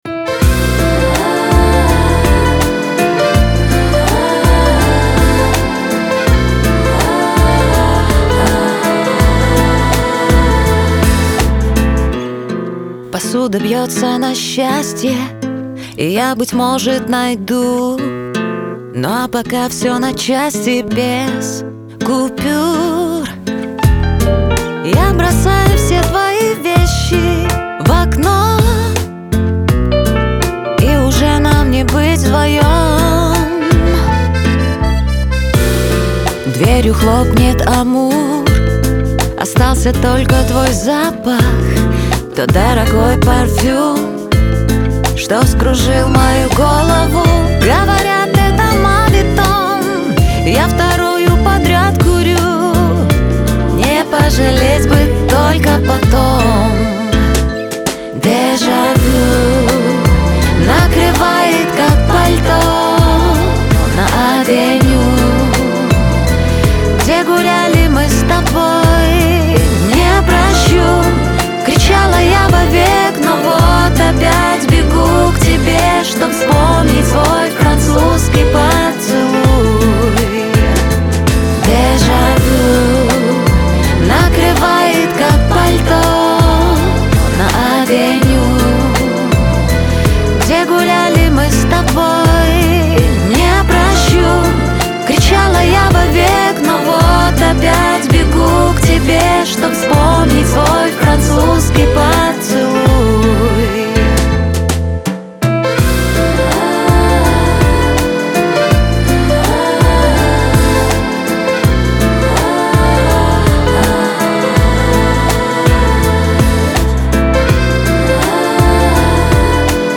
pop
диско